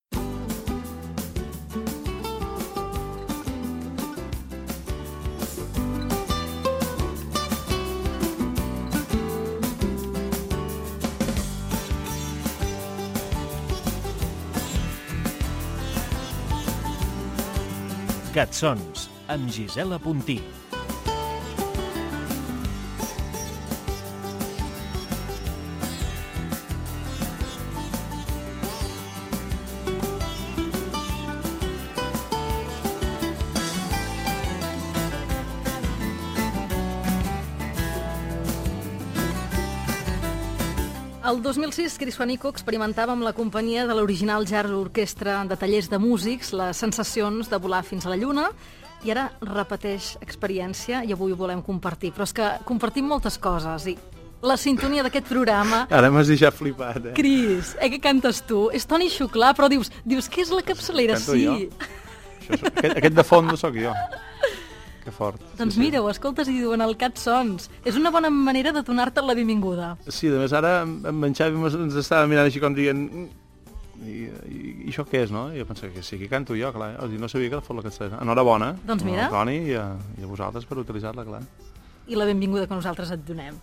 Inici del programa i salutació i benvinguda al cantant menorquí Cris Juanico
Musical